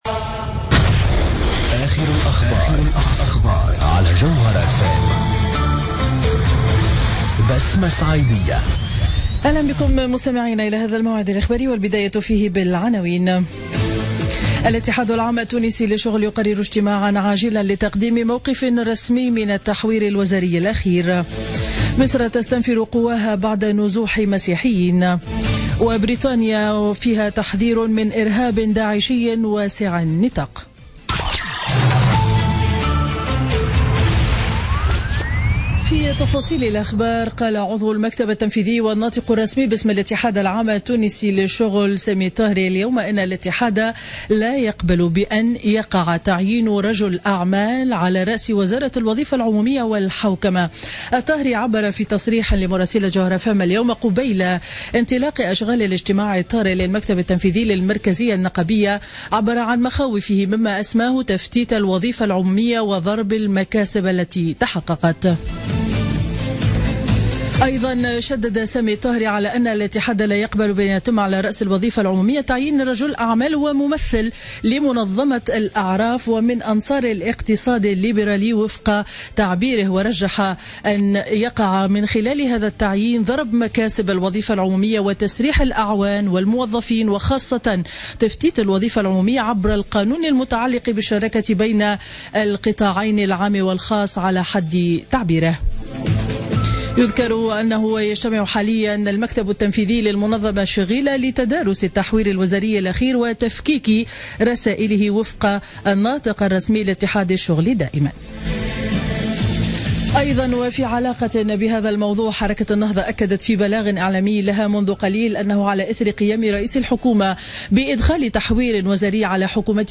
نشرة أخبار منتصف النهار ليوم الأحد 26 فيفري 2017